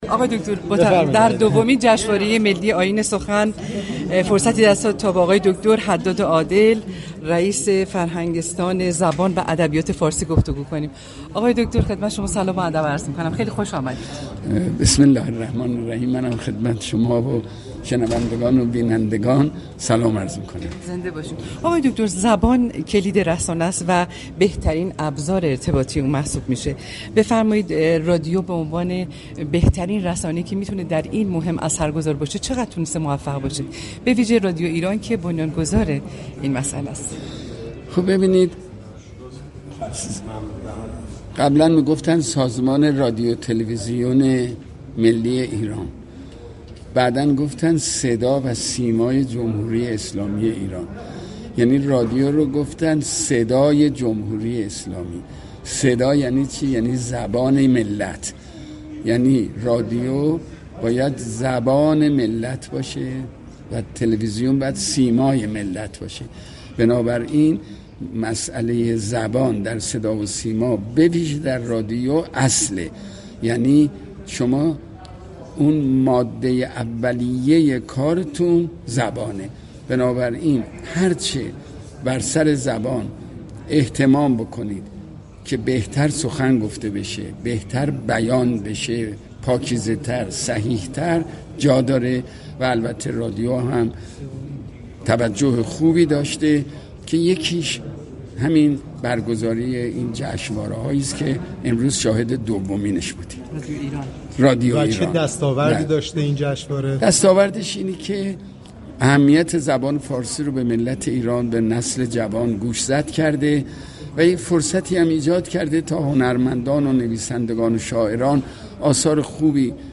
شخصیت مهم خبری: غلامعلی حداد عادل رییس فرهنگستان زبان و ادبیات فارسی